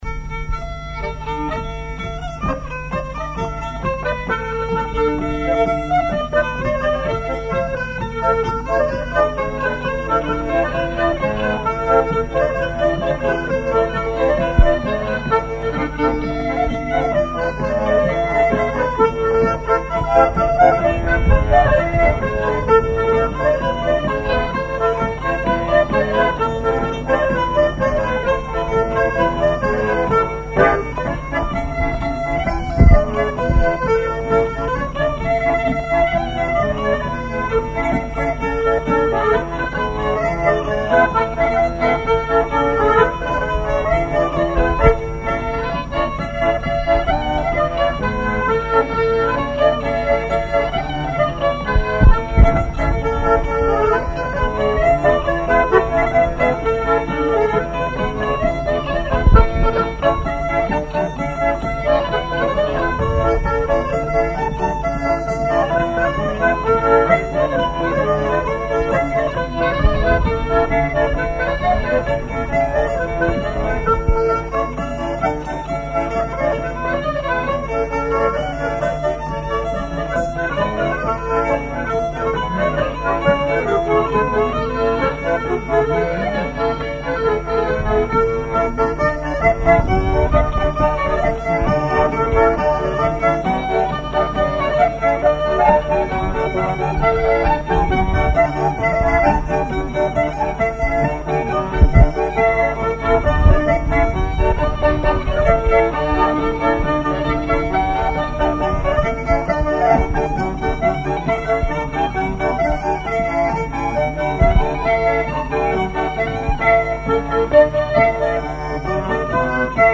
danse : branle : courante, maraîchine
collectif de musiciens pour une animation à Sigournais
Pièce musicale inédite